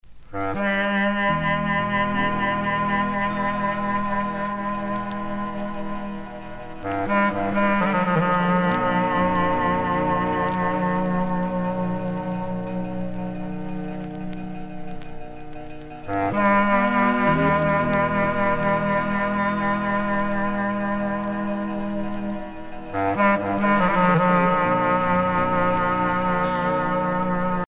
Mourning song of nort Epirus